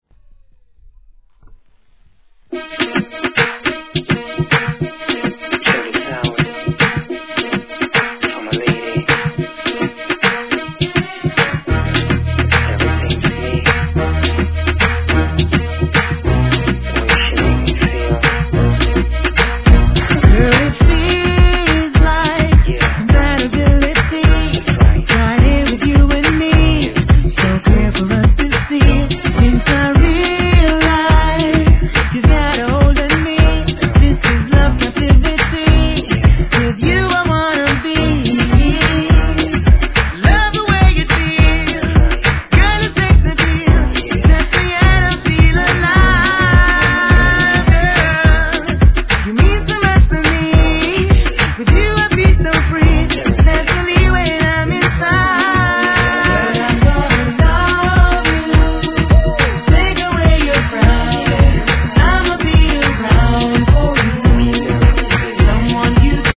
HIP HOP/R&B
彼の甘〜いヴォイスにこのキャッチーなDANCEHALLトラックが、これまた万人受けタイプ!!